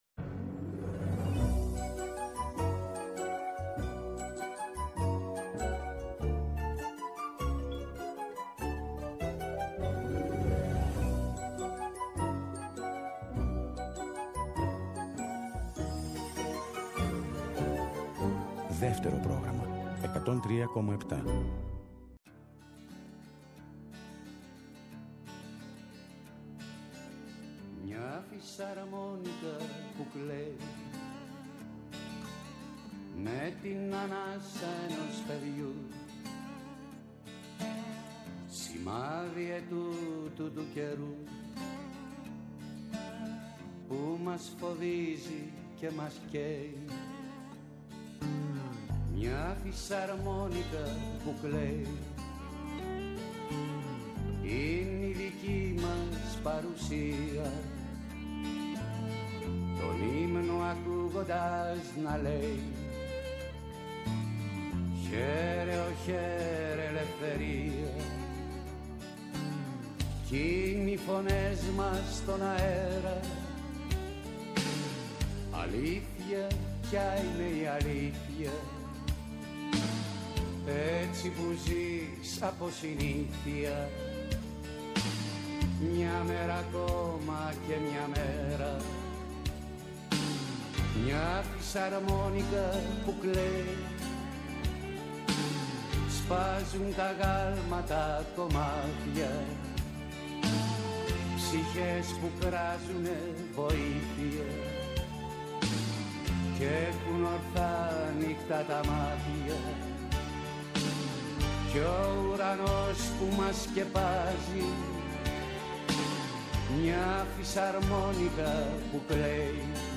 Θα ακούσουμε αποσπάσματα συνέντευξής του 2007 όπου μιλά γι’ αυτή την “ηλεκτρική” πλευρά του, τον Bob Dylan και τον Woody Guthrie. Επίσης θα μεταδοθούν και δυο τραγούδια σε demo εκδοχή με τη φωνή του όπως τα έστειλε στη Νάνα Μούσχουρη πριν τα ηχογραφήσει εκείνη.